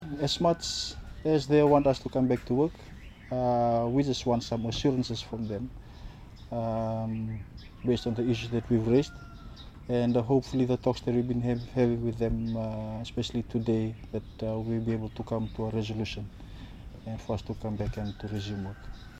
Traffic-Controllers-Radio-Grab-2019.mp3